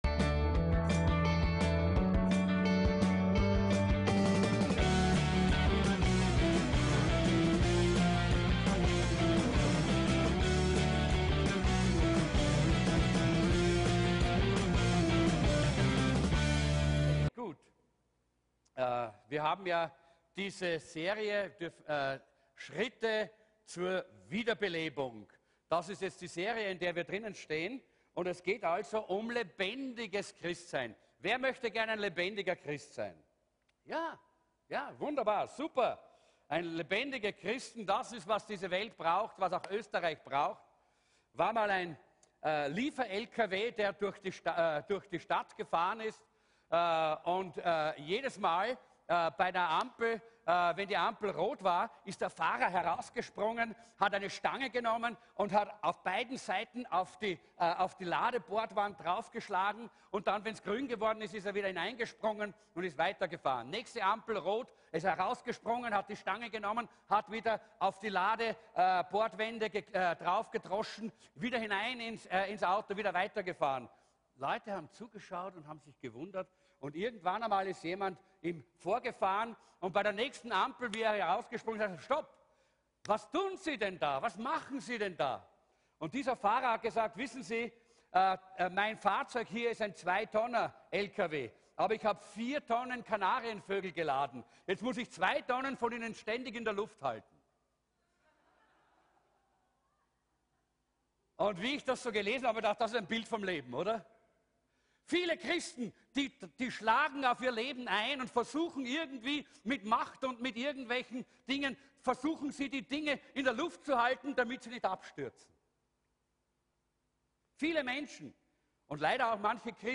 LASS LOS ~ VCC JesusZentrum Gottesdienste (audio) Podcast